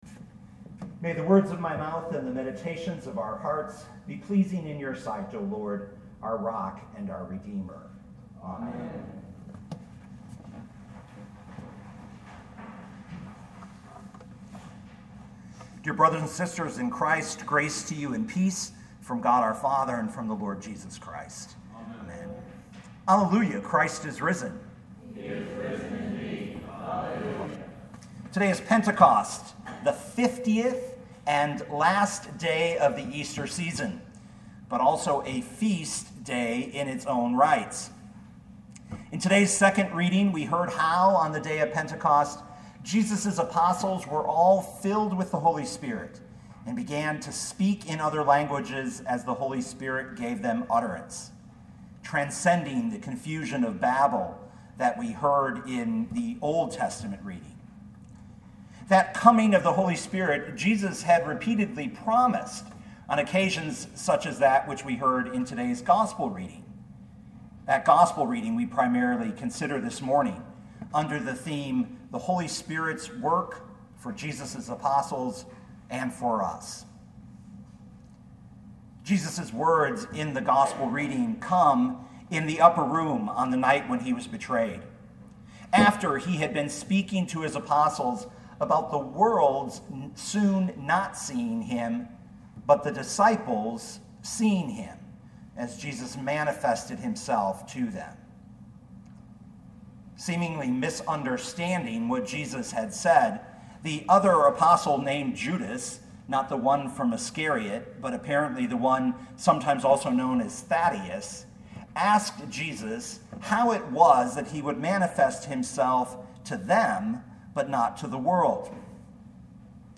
2019 John 14:23-31 Listen to the sermon with the player below, or, download the audio.